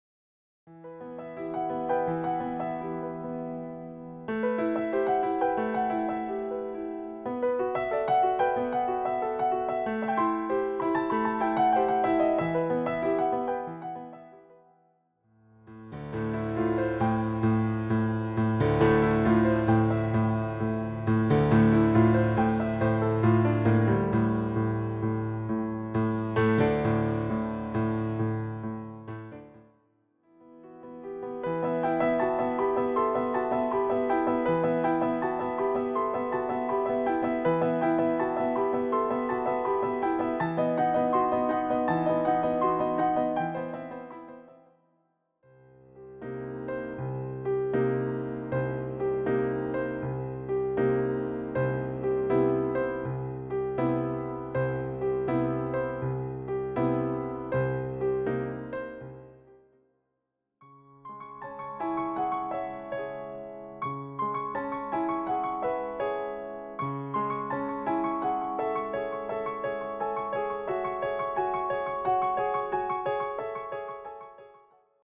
piano pieces